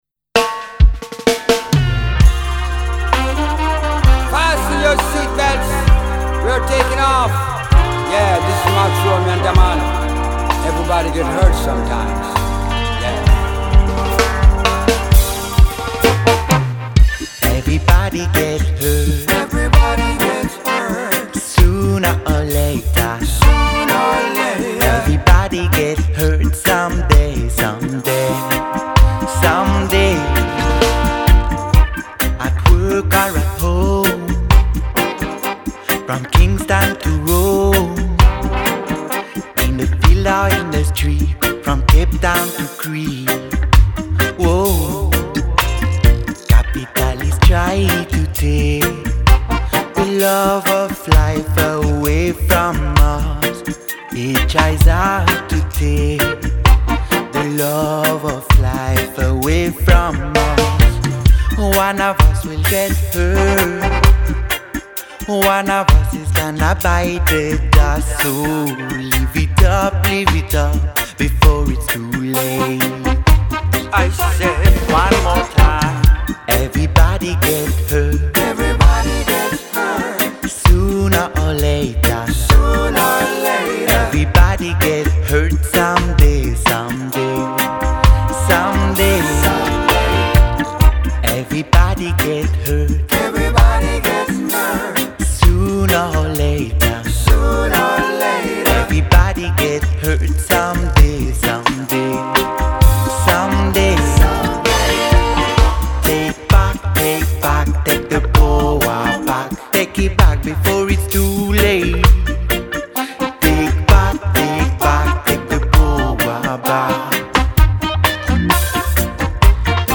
Recorded in Paris
one drop single
Genre: Reggae